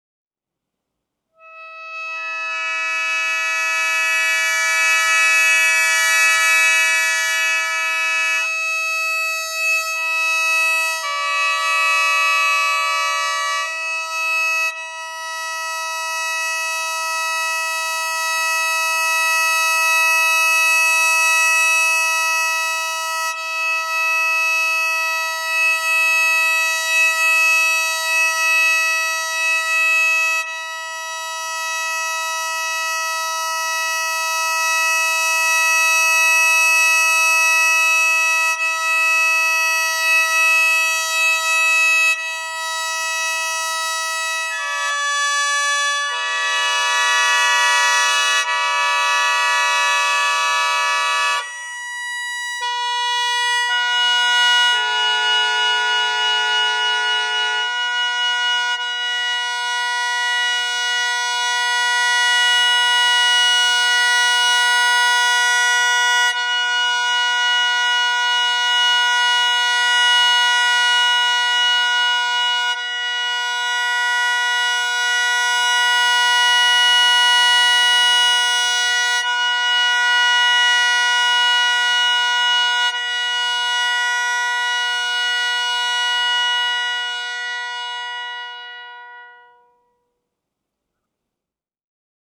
笙ソロ
Sho-2.wav